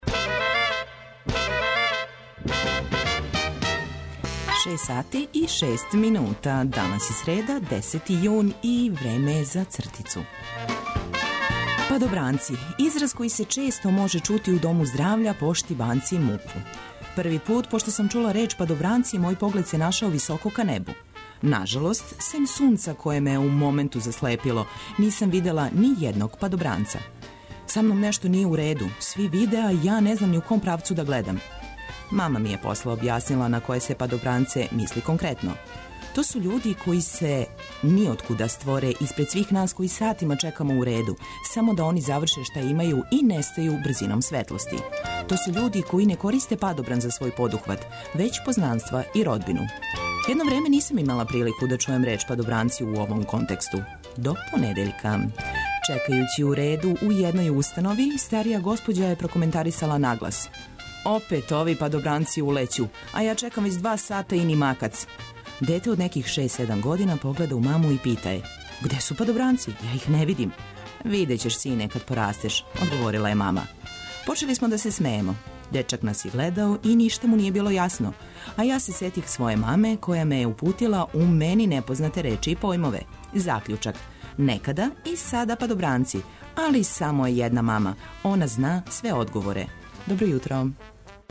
Добра музика, сервисне, културне и спортске информације, прошараће још један Устанак од 6 до 9.